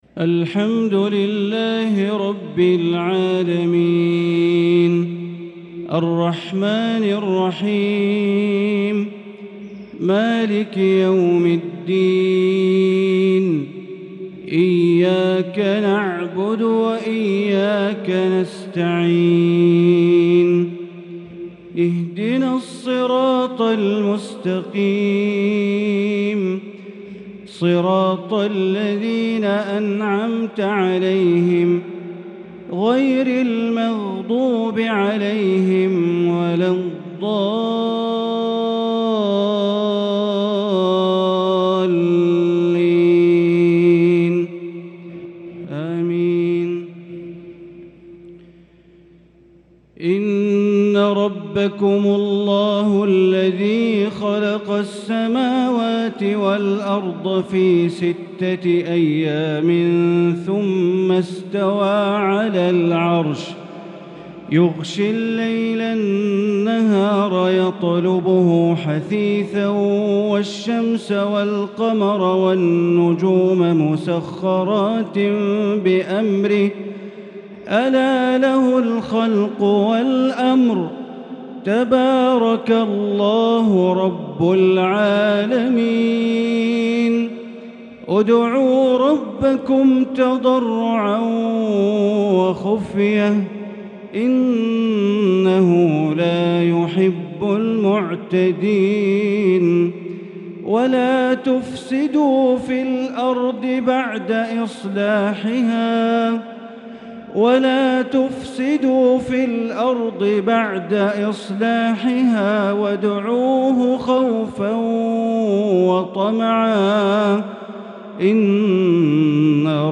عشاء السبت 1 رمضان 1443هـ من سورتي الأعراف و الإسراء | isha prayer from Surat Al-Araf and Al-Israa’ 2-4-2022 > 1443 🕋 > الفروض - تلاوات الحرمين